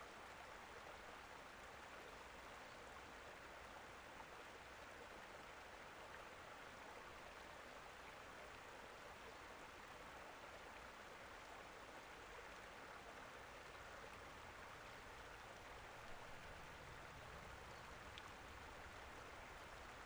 pond-ambience.wav